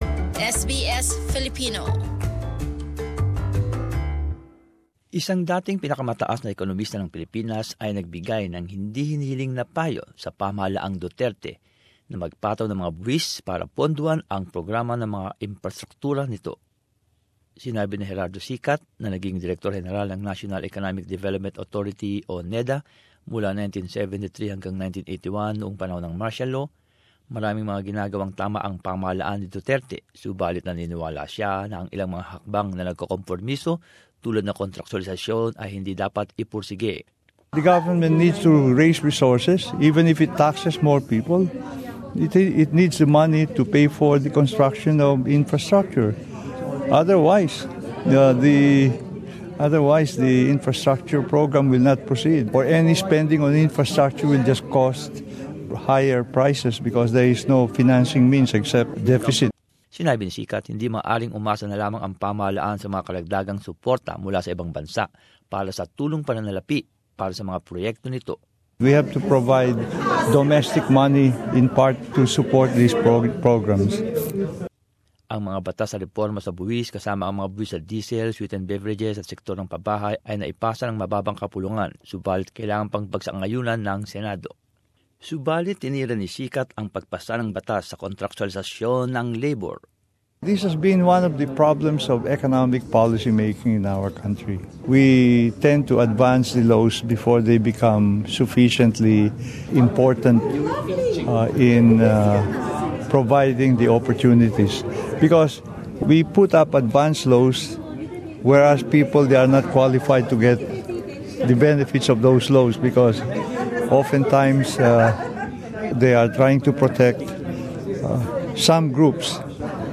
He spoke to SBS Filipino on the side of his inaugural Peace Address of the United Nations Association of Australia (UNAA) in Sydney.